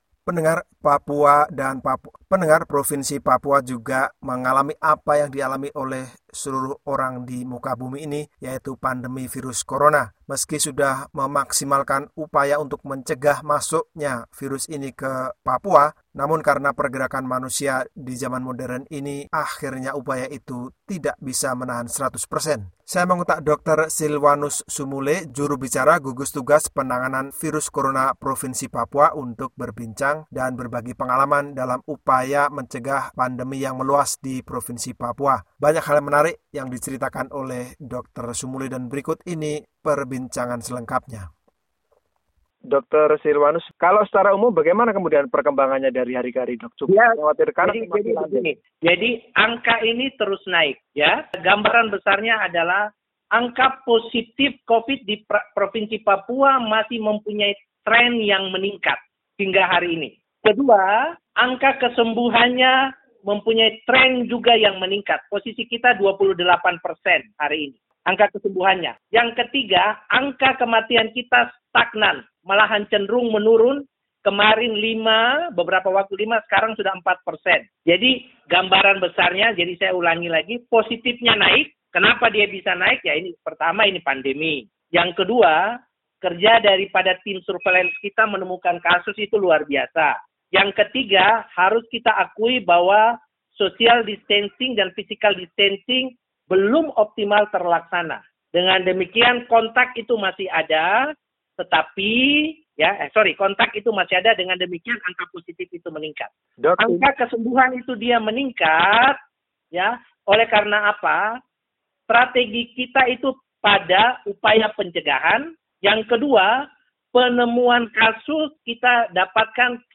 wawancara_jumat_1_mei_2020.mp3